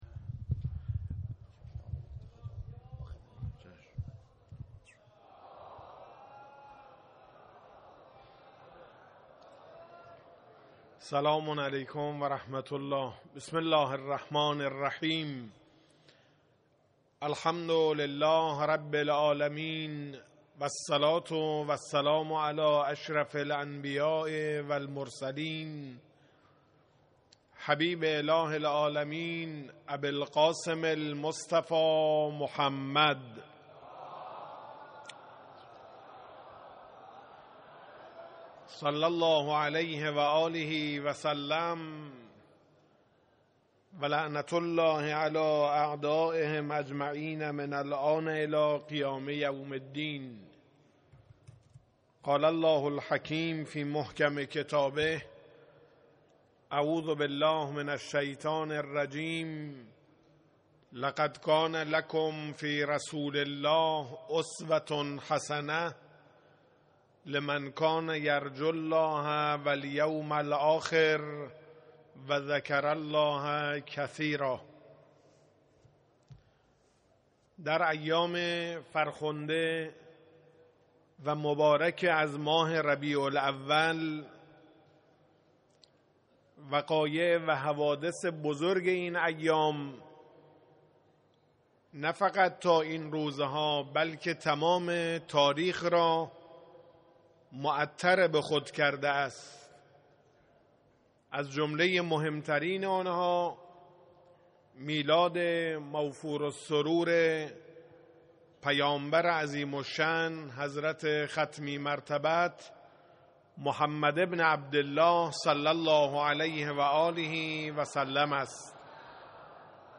92.11.4-سخنران.mp3